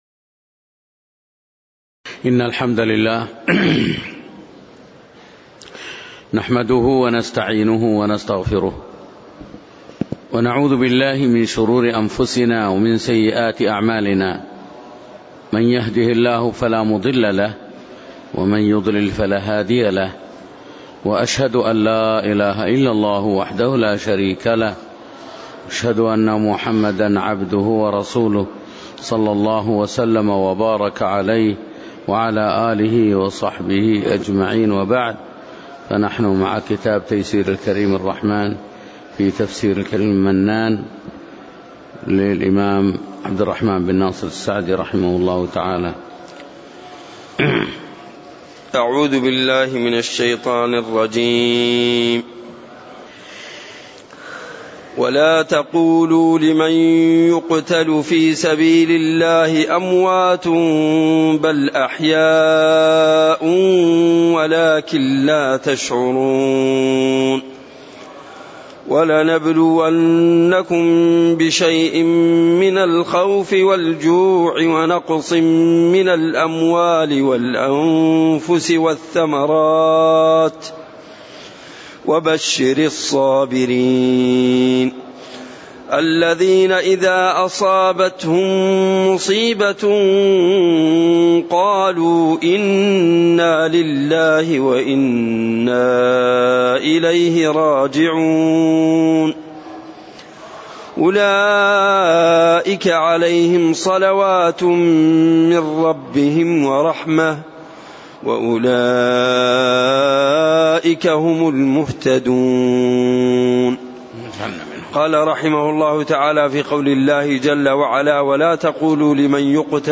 تاريخ النشر ٢٦ جمادى الآخرة ١٤٣٨ هـ المكان: المسجد النبوي الشيخ